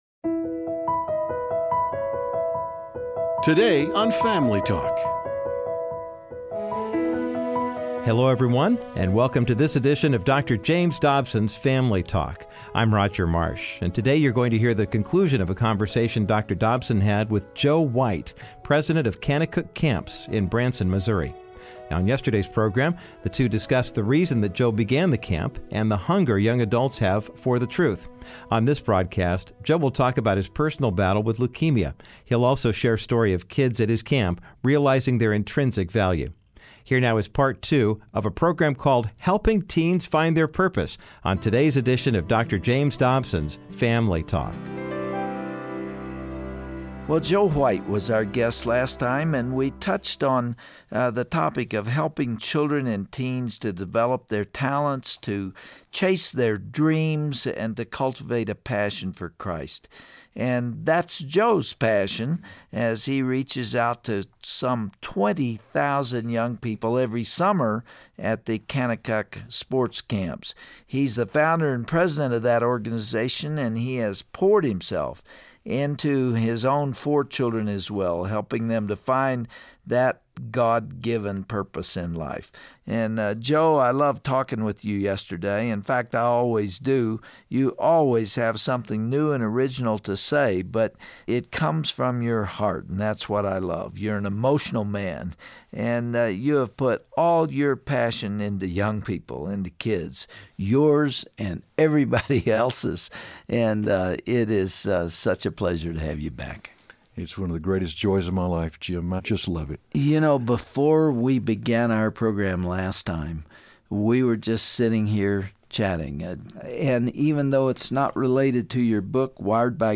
Listen to this captivating interview on todays edition of Dr. James Dobsons Family Talk.